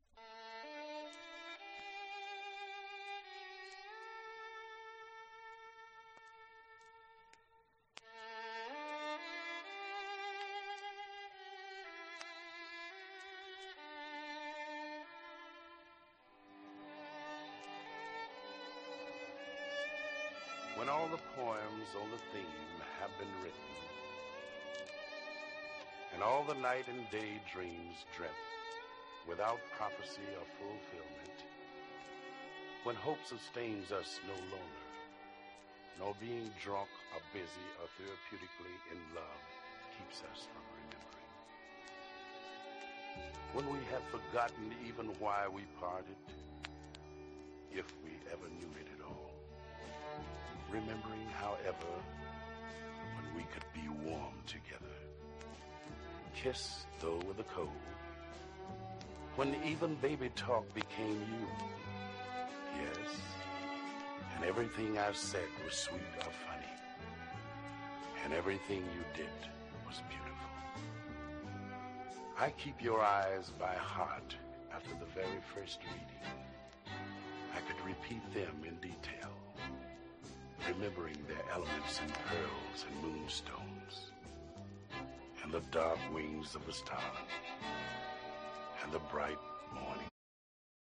ポピュラーボーカル的にバリトン・ヴォイスを響かせる黒人シンガー
ブラック・ムービーのサントラみたいで格好良いです。